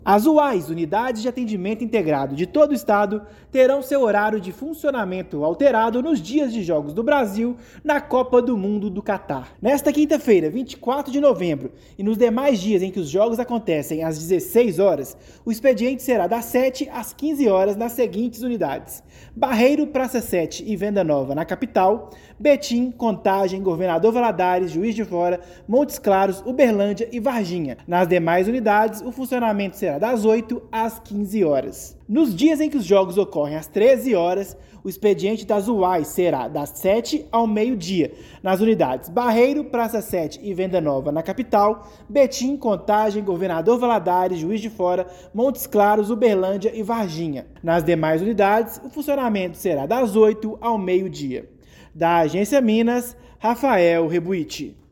UAIs terão expediente alterado nos dias de jogos do Brasil na Copa. Ouça matéria de rádio.